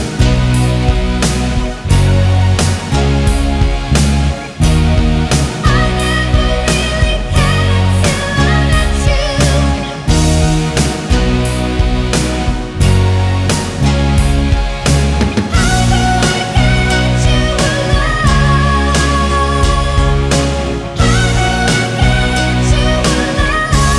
Two Semitones Down Pop (1980s) 3:38 Buy £1.50